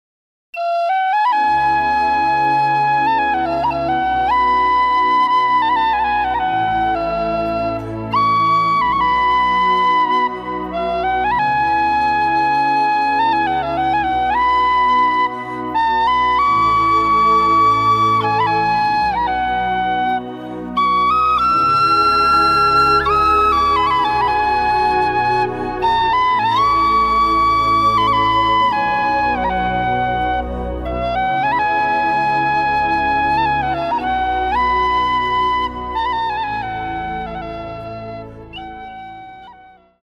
Love CLASSIC INSTRUMENTAL melodies.